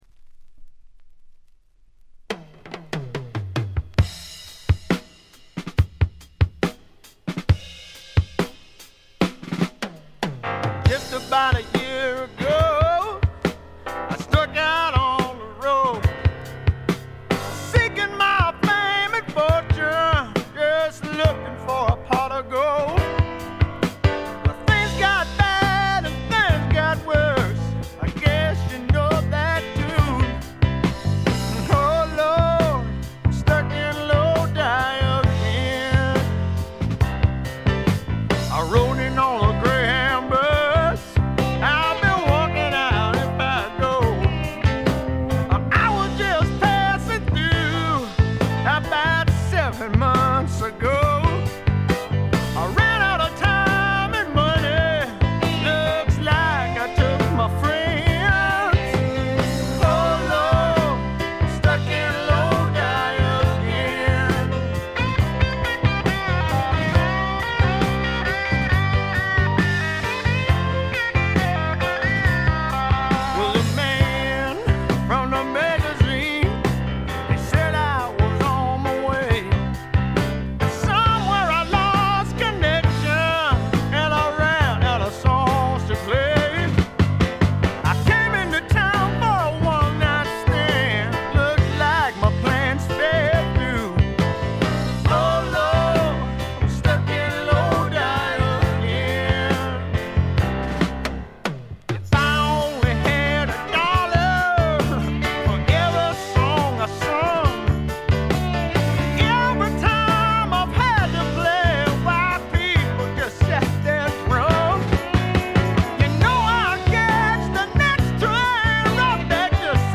部分試聴ですがほとんどノイズ感無し。
70年代末期にあって、あっぱれなスワンプ魂（ザ・バンド魂）を見せてくれました。
試聴曲は現品からの取り込み音源です。